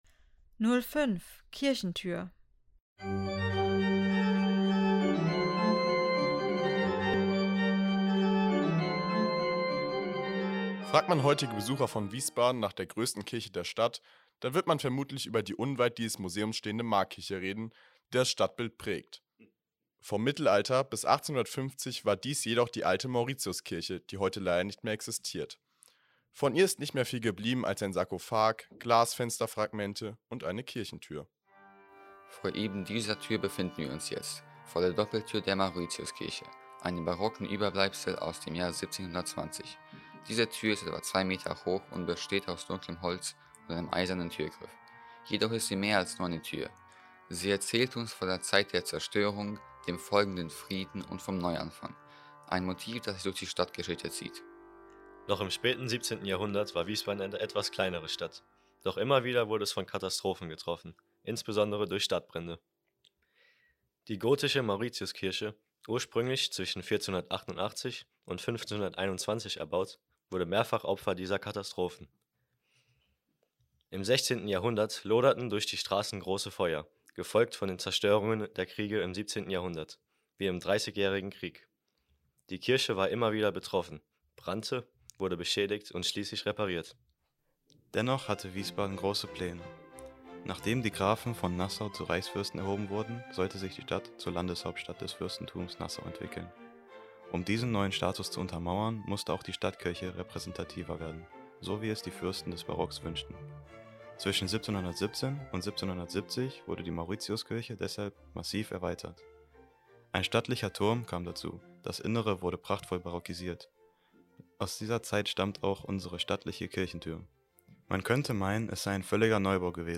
Audioguide - Kirchentür